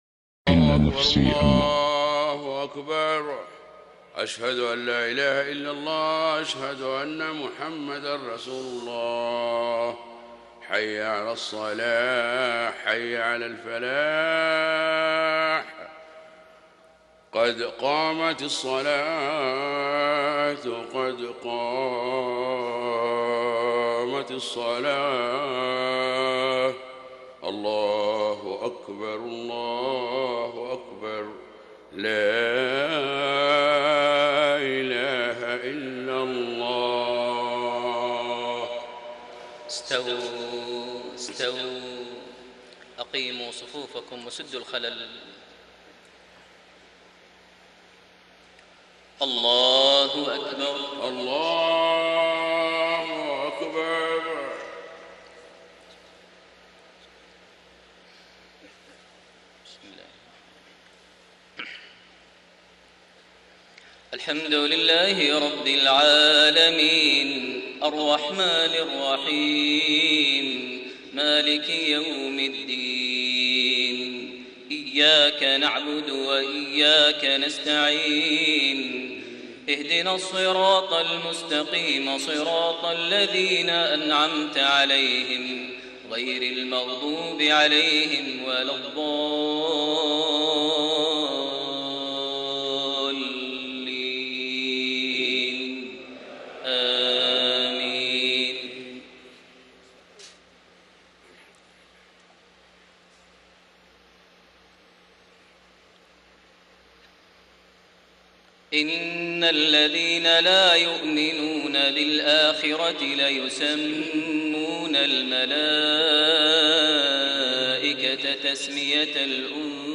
صلاة العشاء 8 شوال 1433هـ من سورة النجم 27-55 > 1433 هـ > الفروض - تلاوات ماهر المعيقلي